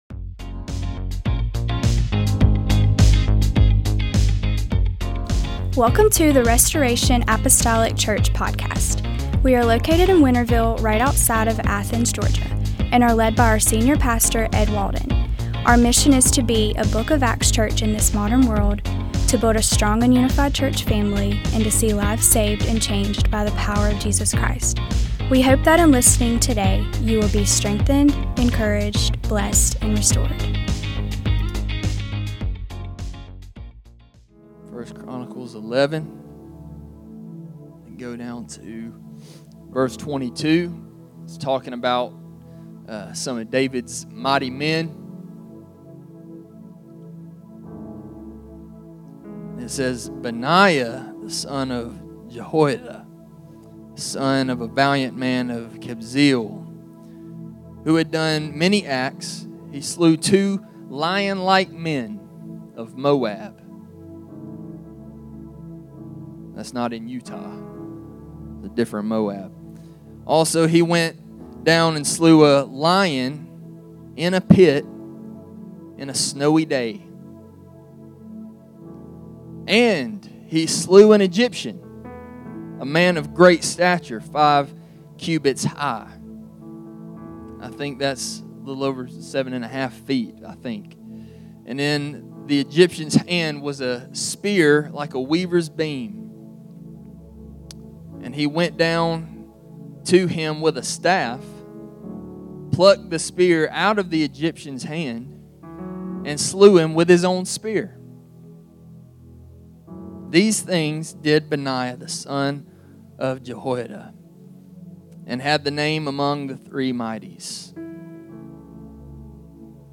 Sunday Service - 03/09/2025 - Asst.